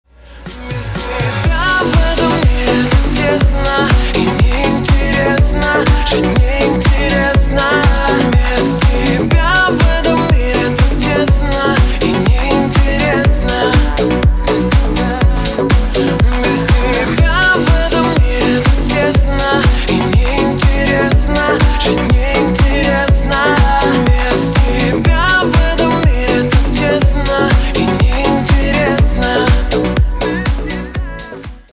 • Качество: 192, Stereo
мужской вокал
грустные